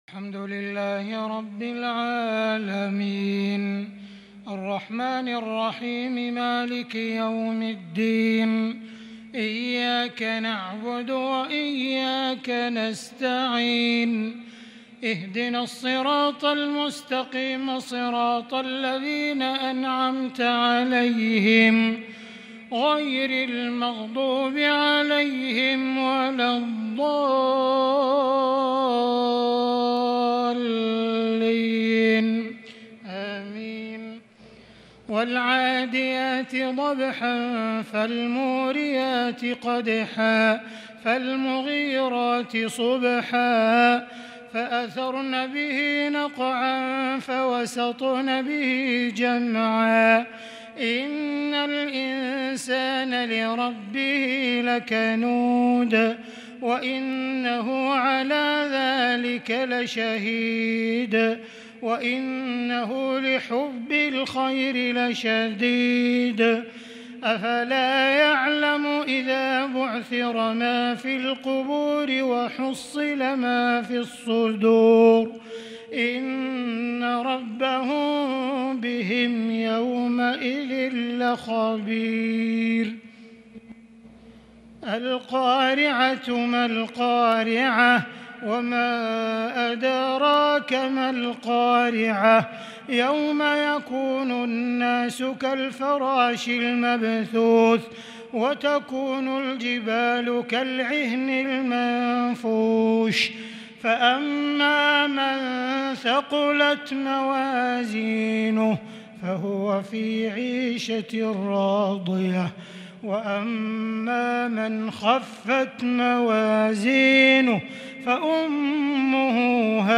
صلاة التهجد | ليلة 29 رمضان 1442ھ | من سورة العاديات إلى سورة الناس | tahajud prayer The 29rd night of Ramadan 1442H | > تراويح الحرم المكي عام 1442 🕋 > التراويح - تلاوات الحرمين